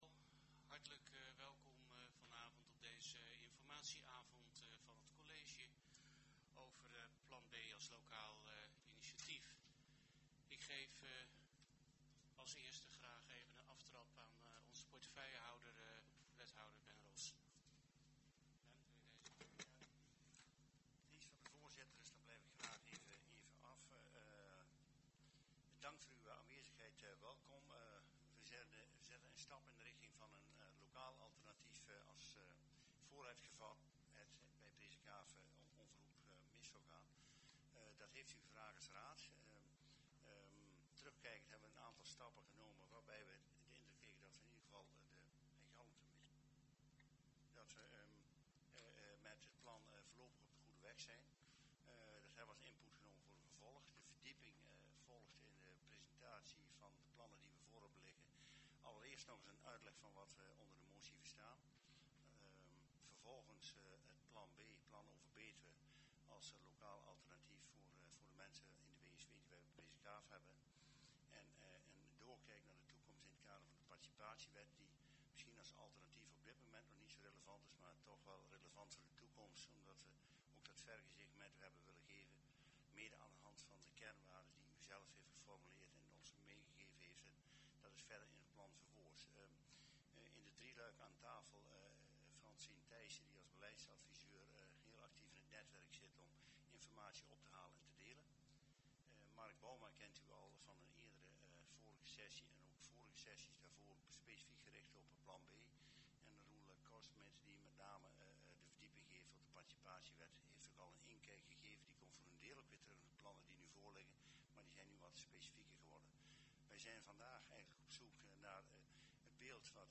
Commissiekamer, gemeentehuis Elst